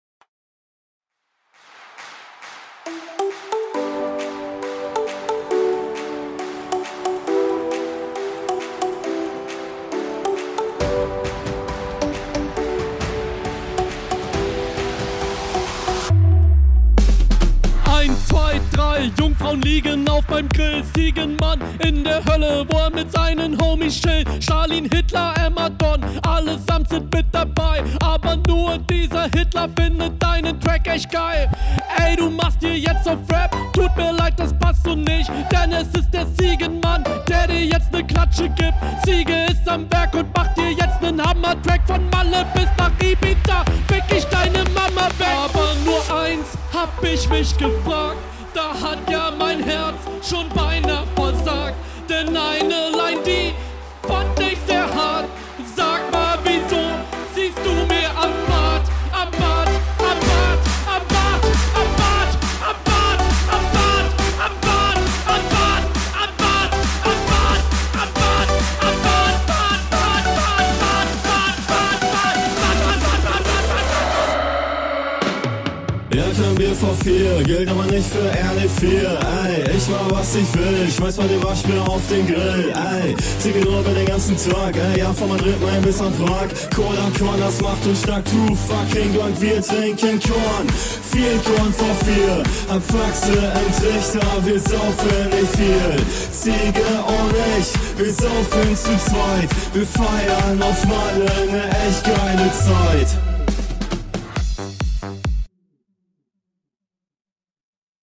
Soundtechnisch gefällts mir mehr.